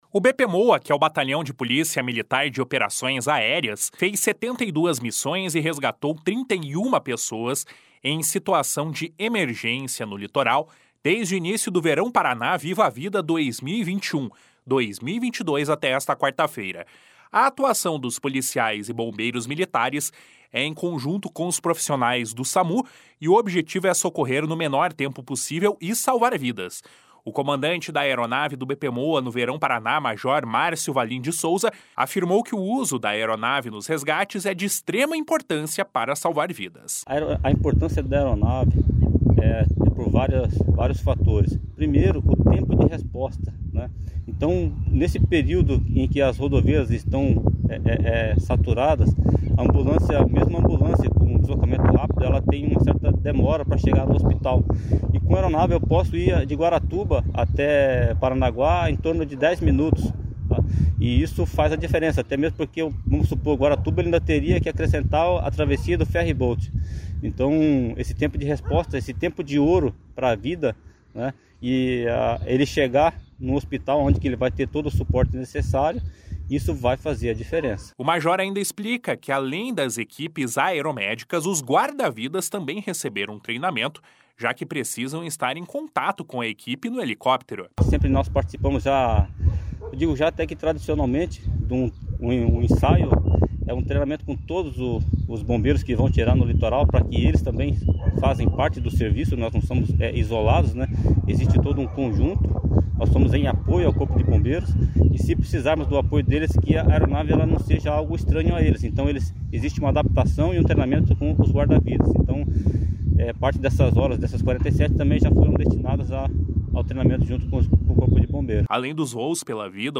Além dos voos pela vida, o BPMOA também fez 20 missões em operações policiais, apoios em buscas a suspeitos, monitoramento de rodovias, translados e treinamentos. (Repórter: